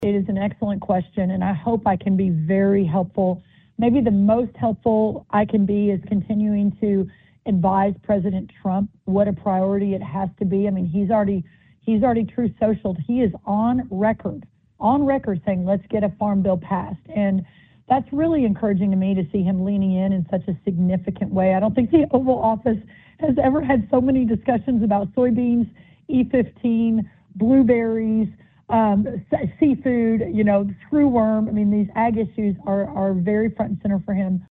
In an interview with the Iowa Agribusiness Radio Network, U.S. Agriculture Secretary Brooke Rollins said those updates were included in the same H.R. 1 legislation passed last summer that also addressed tax policy affecting agriculture.